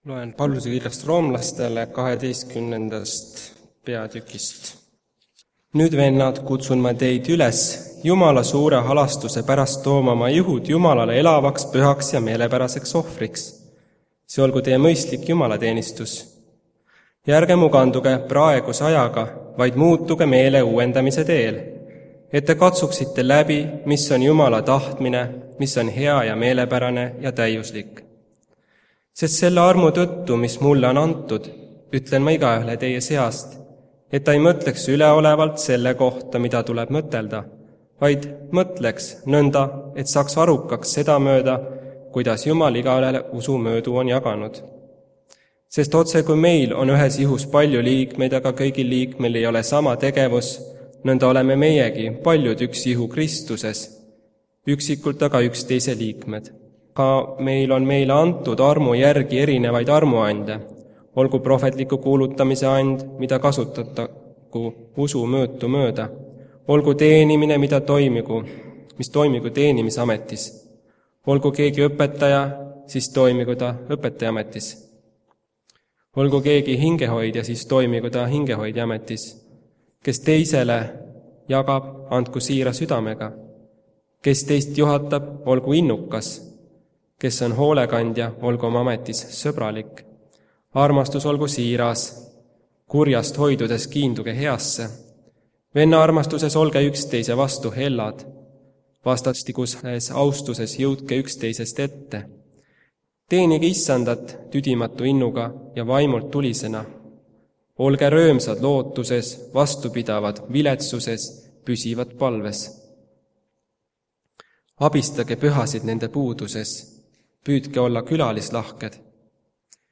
Täna on jutlus koguduse teemal JUMALA IHU - KOGUDUS Lauluhetk aastast 1976
Jutlused